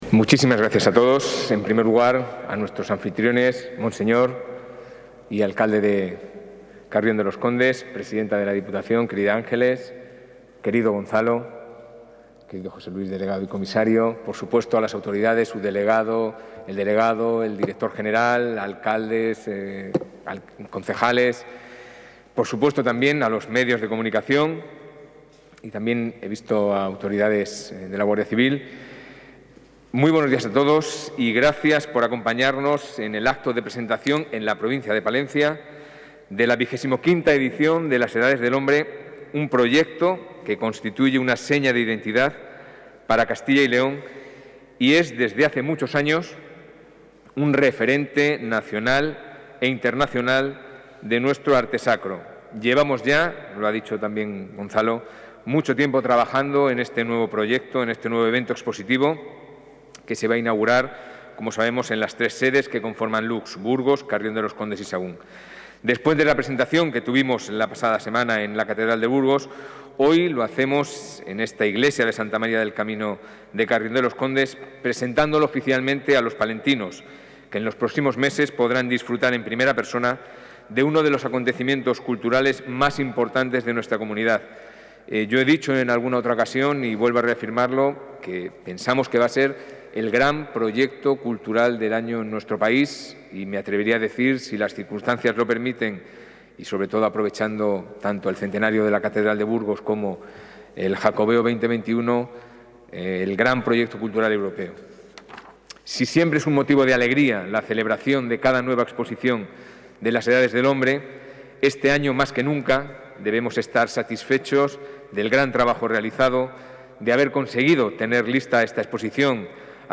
El consejero de Cultura y Turismo, Javier Ortega, ha presentado hoy en Carrión de los Condes la XXV edición de Las Edades del Hombre,...
Intervención del consejero de Cultura y Turismo.